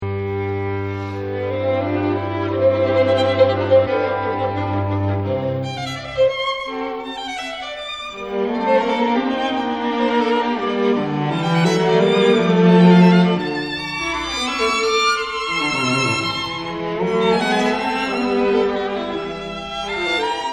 muziektheorieanalyse klassieke stukken  > Beethoven: strijkkwartet in F gr.t.  op. 59 nr.1